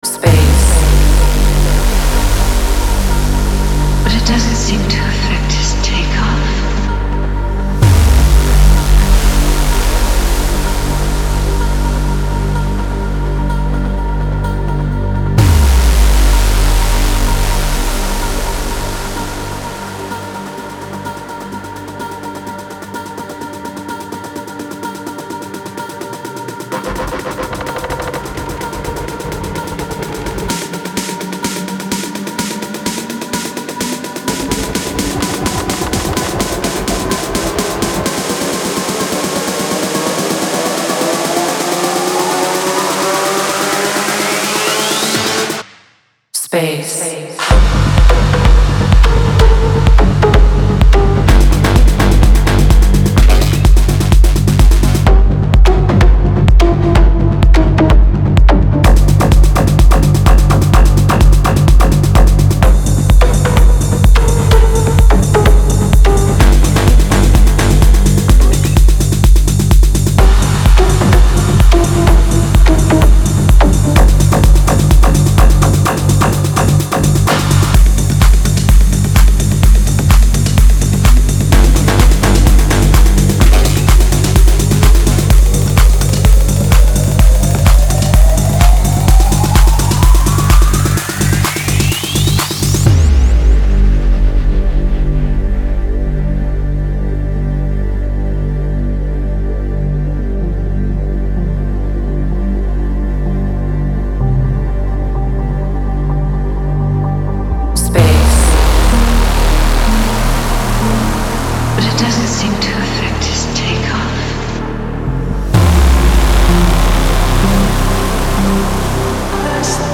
• Жанр: Dance, EDM